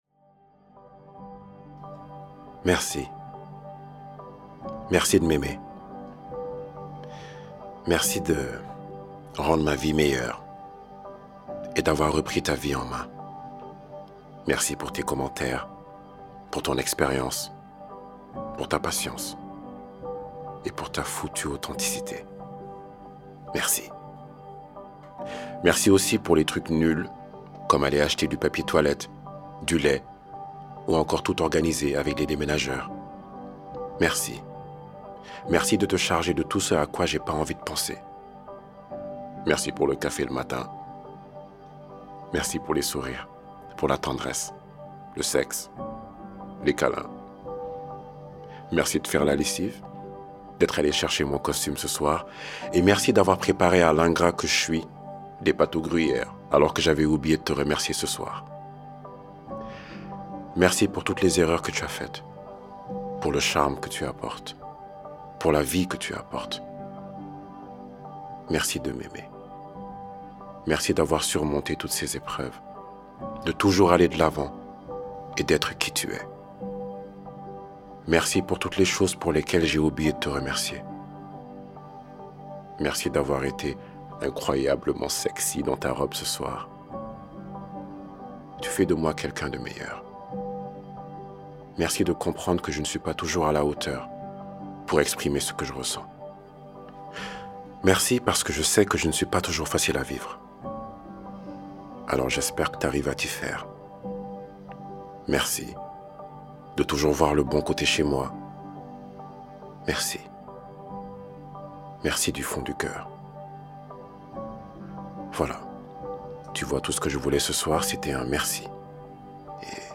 Voix-off
20 - 40 ans - Baryton-basse